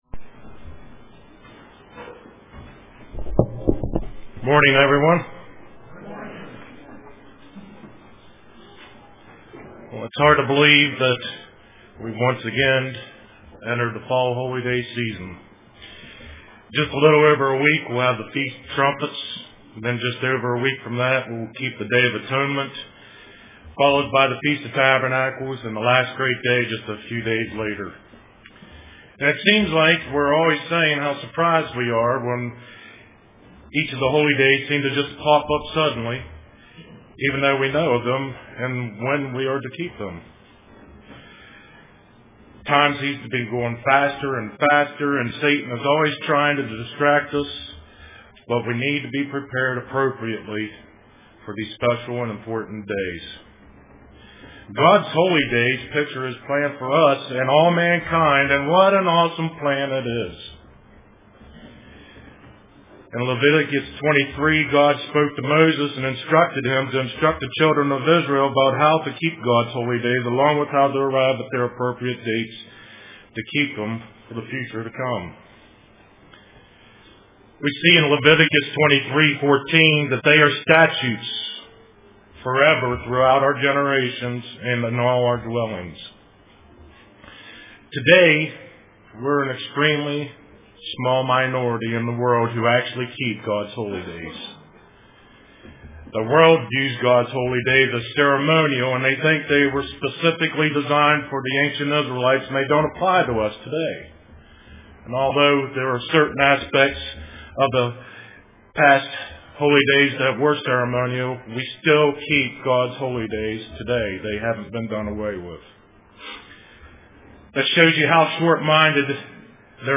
Print Keeping the Holy Days UCG Sermon Studying the bible?